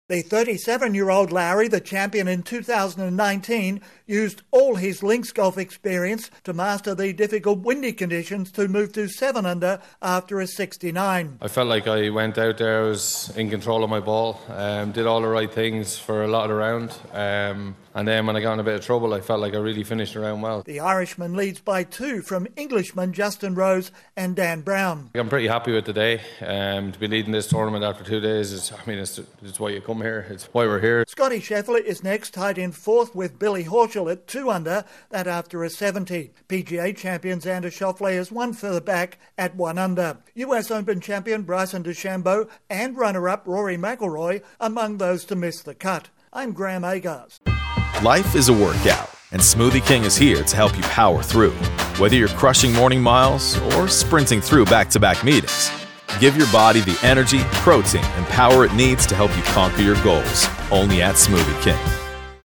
Ireland's Shane Lowry has taken a two shot lead after 36 holes of the British Open at the Royal Troon course in Scotland. Correspondent